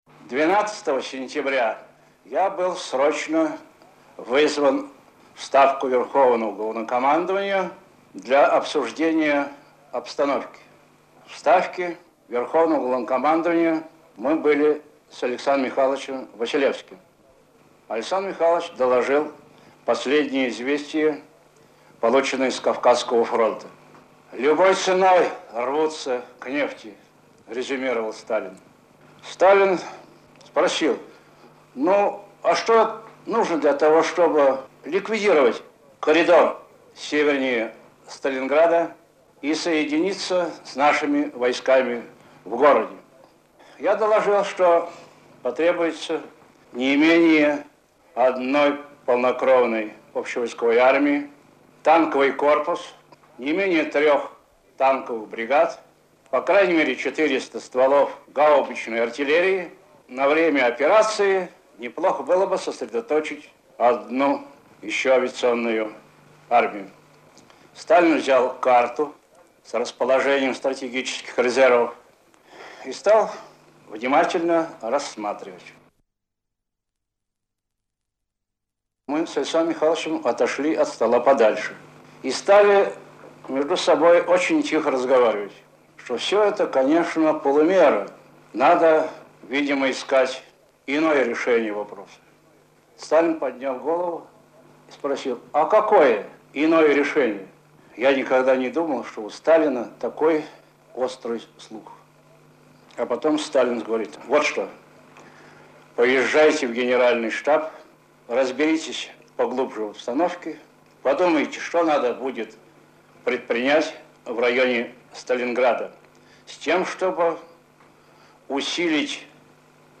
Маршал Советского Союза Георгий Жуков вспоминает о разработке операции «Уран» (Архивная запись).